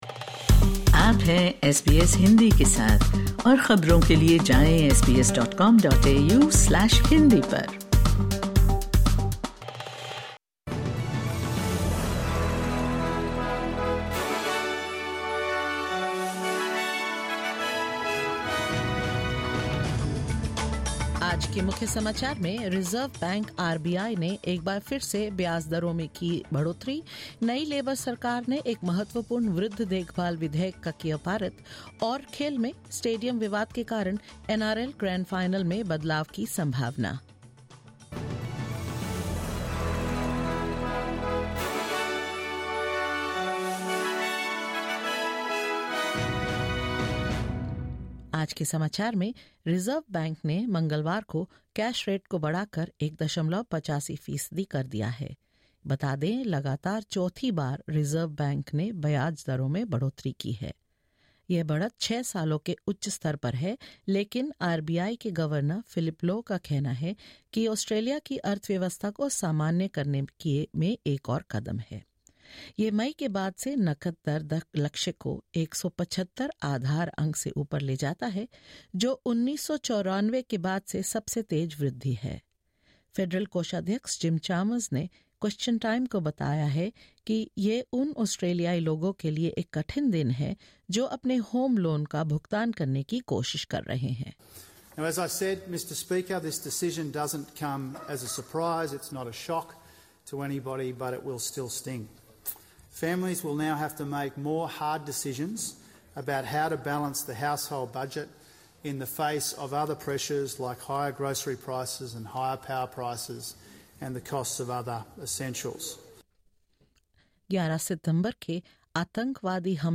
In this latest SBS Hindi bulletin: Reserve Bank lifts cash rates to 1.85, the fourth interest rate rise in a row; Labor government’s Aged Care Reform Bill becomes the first bill to pass the new Parliament; In sports, NRL weighs up a grand final shift to Queensland over a stadium dispute and more.